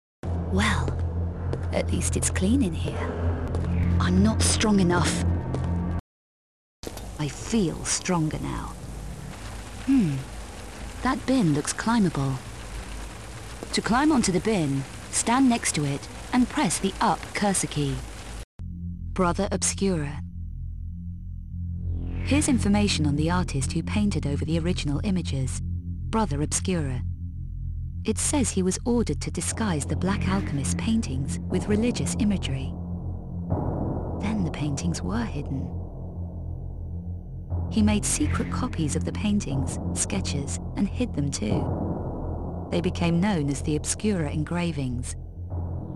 Голос в игре
Голос Лары Крофт    1,05 MB